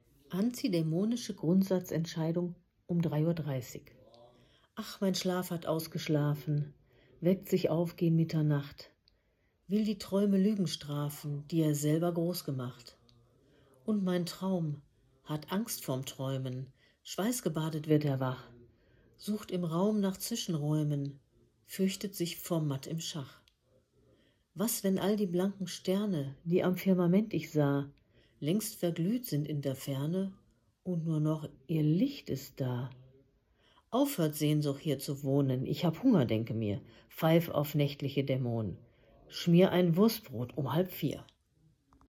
🎤 Audio Lesung